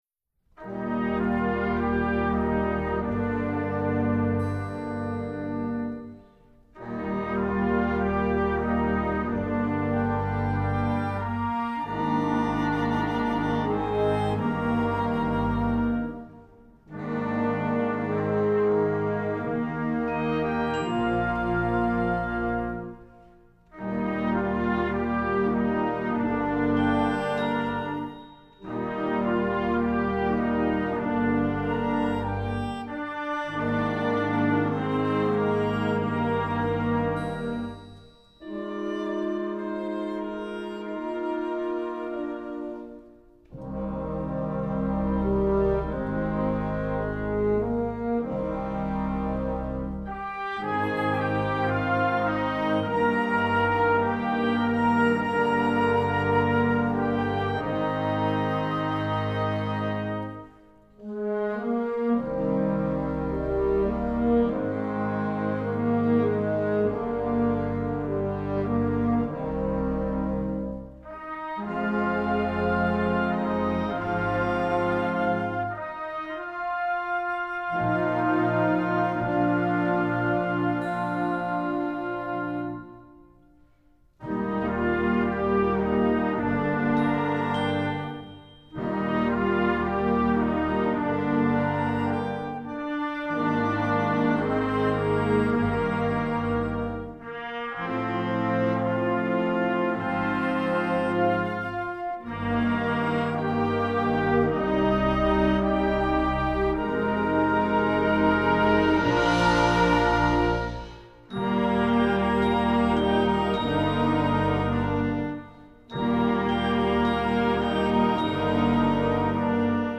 film/tv, movies